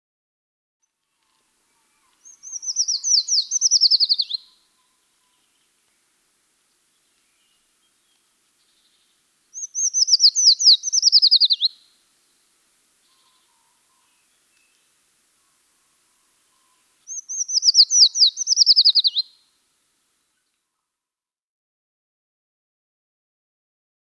Mikä lintu tässä laulaa?